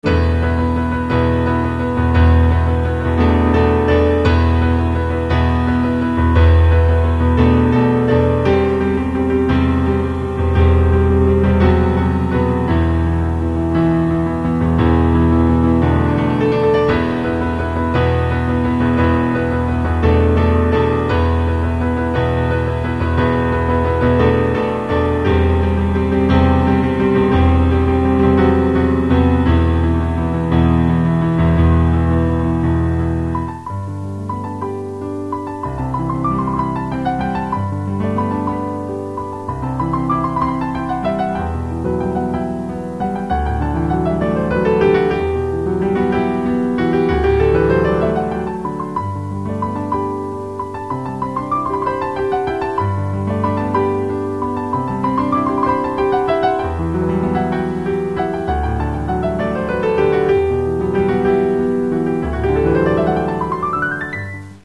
Каталог -> Классическая -> Фортепиано